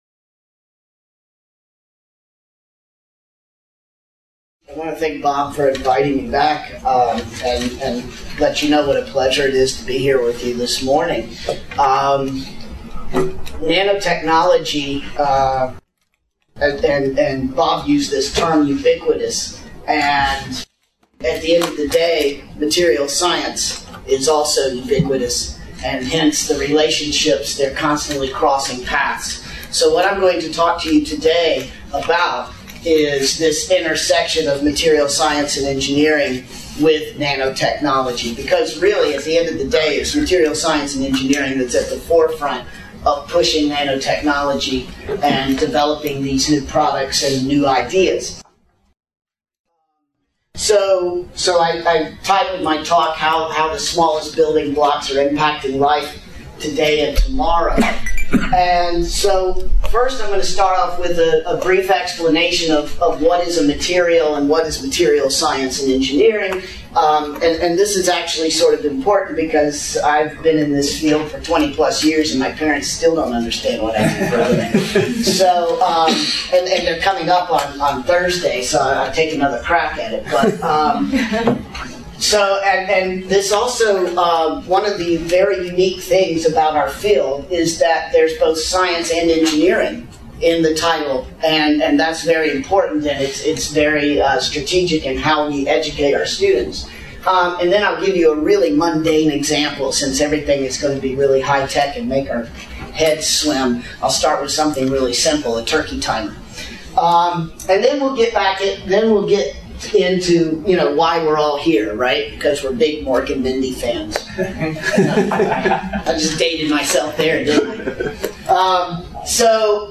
This webinar, published by the Nanotechnology Applications and Career Knowledge Support (NACK) Center at Pennsylvania State University, focuses on the intersection of materials science and engineering with nanotechnology.